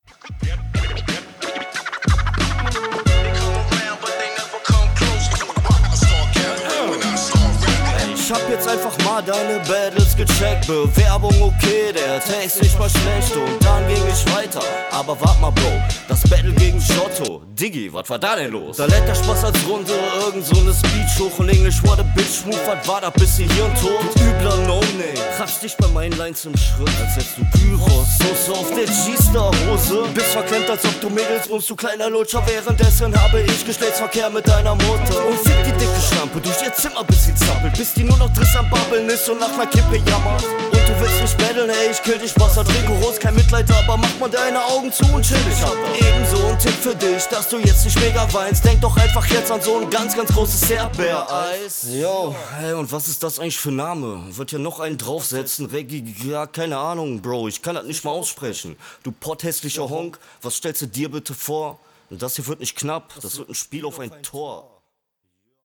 Schöner Oldschool Vibe und Stimme passt auch sehr gut dazu.